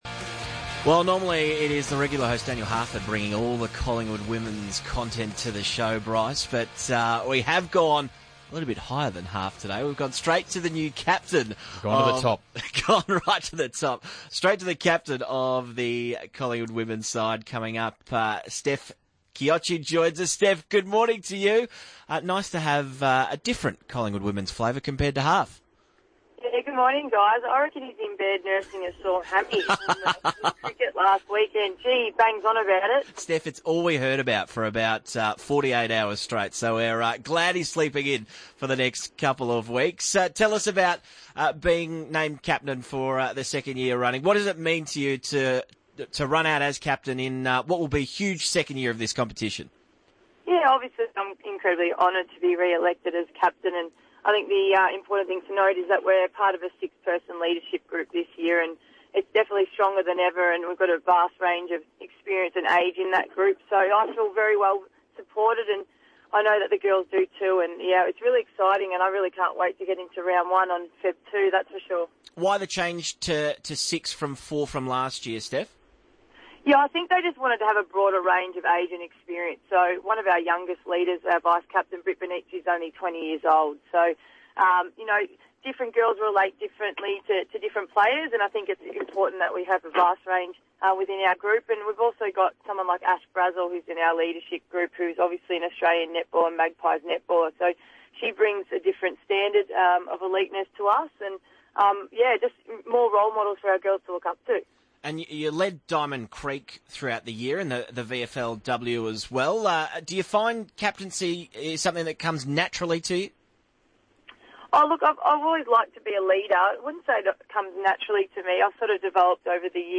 Listen to Steph Chiocci speak to the RSN Breakfast team after her re-appointment as Collingwood AFL Women's captain for the upcoming season.